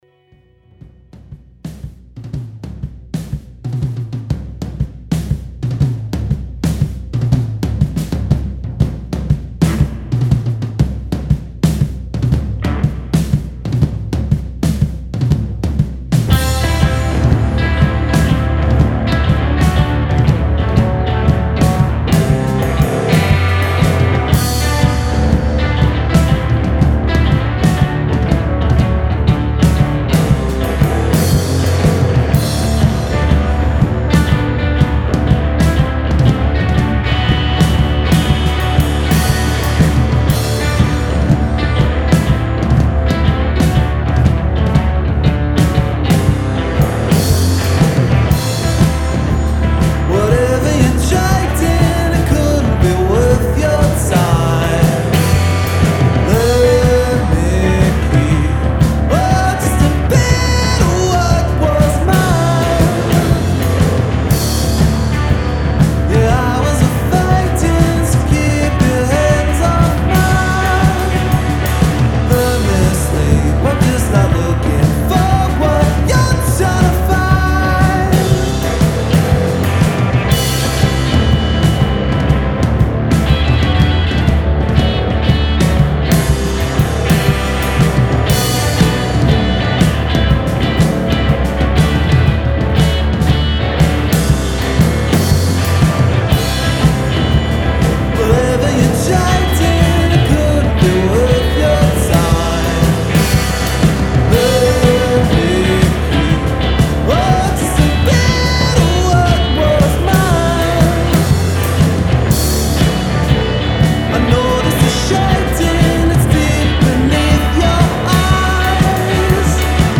rock group
It’s an energetic song with a lot of soul and passion.